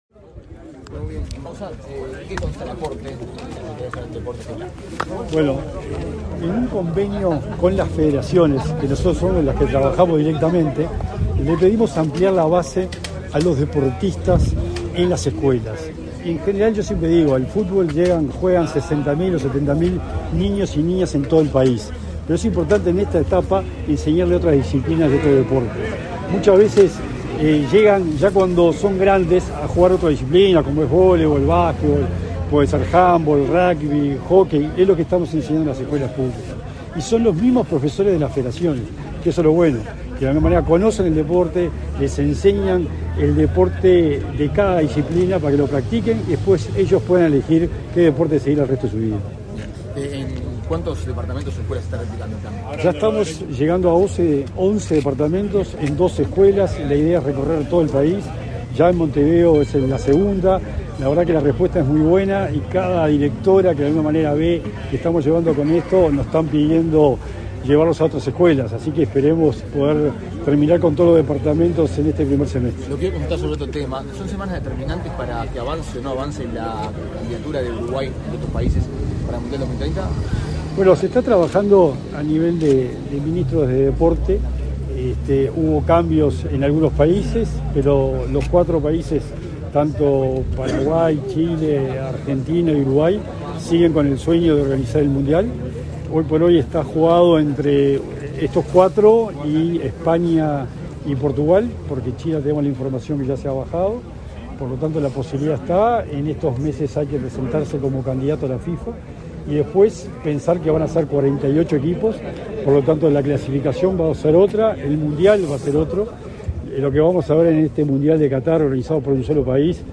Declaraciones a la prensa del secretario nacional del Deporte, Sebastián Bauzá
Tras el evento, Bauzá efectuó declaraciones a la prensa